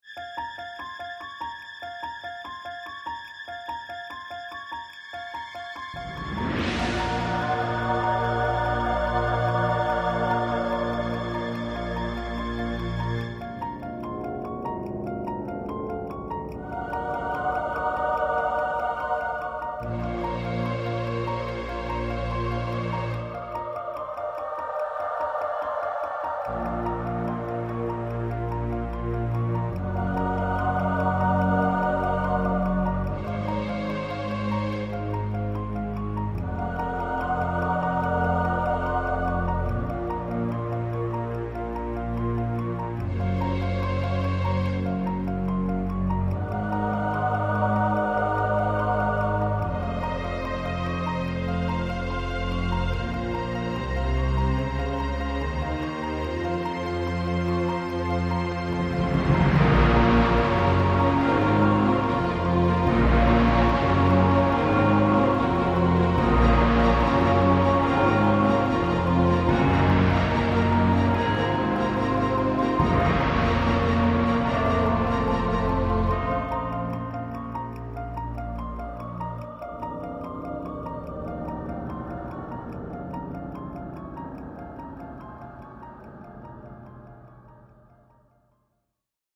The ULTIMATE haunted house CD!